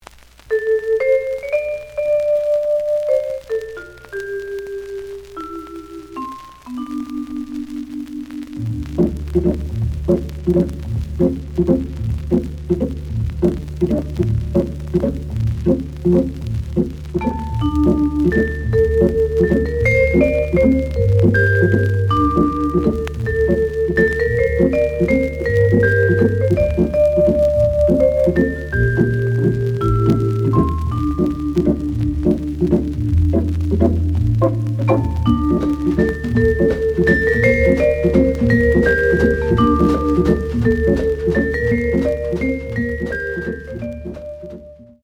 The audio sample is recorded from the actual item.
●Genre: Cool Jazz
Some noise on both sides.